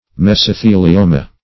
mesothelioma - definition of mesothelioma - synonyms, pronunciation, spelling from Free Dictionary
mesothelioma.mp3